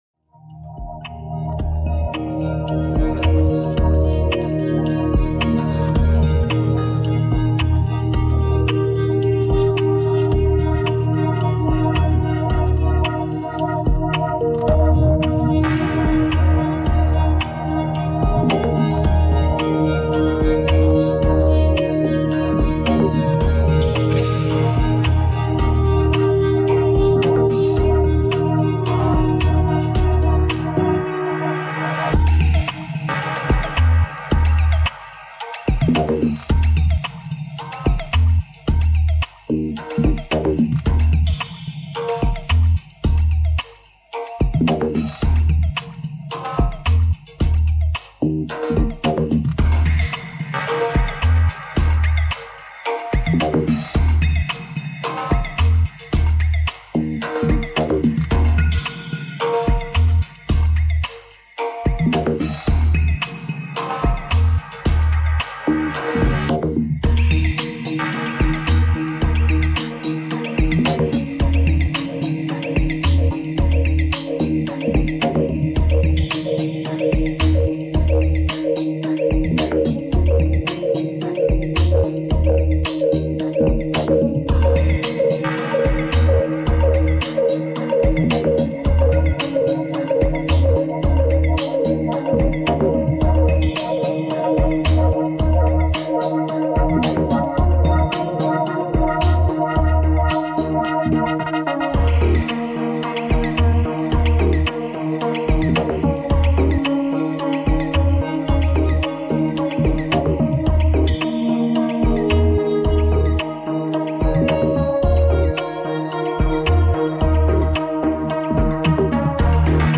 Аудиокнига Чарльз Стросс — Бродячая ферма